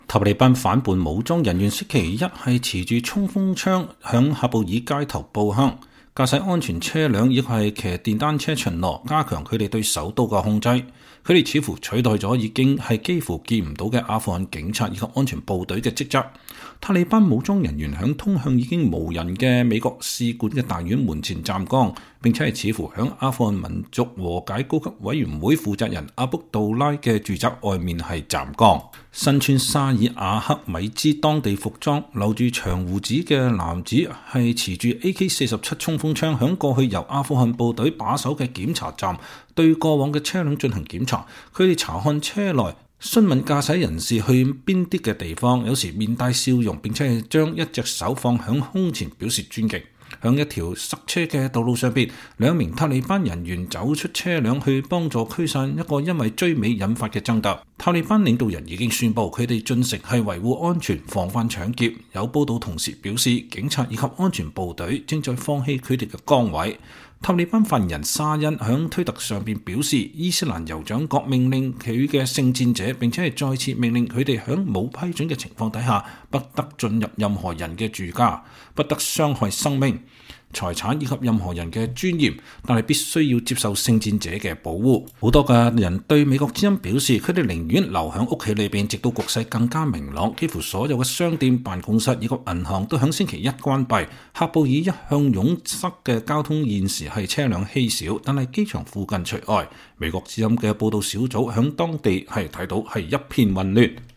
實地報導：塔利班武裝人員在喀布爾街頭持槍巡邏